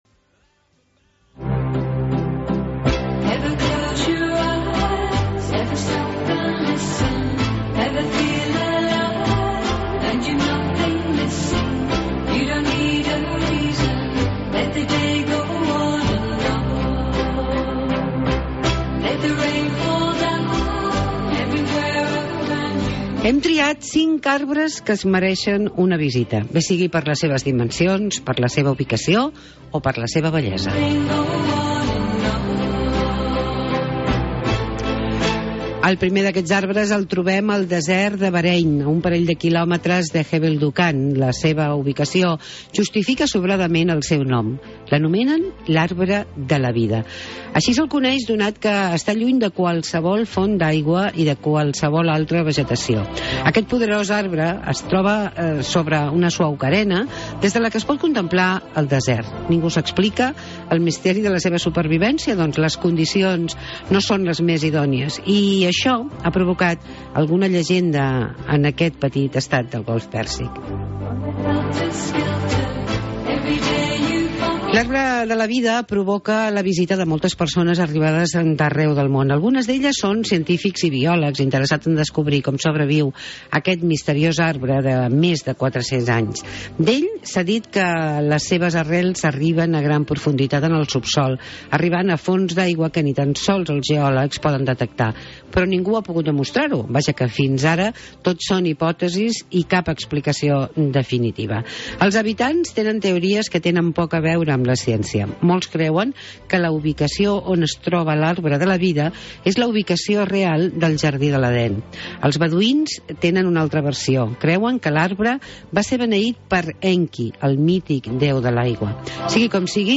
Reportatge: Arbres que hem de salvar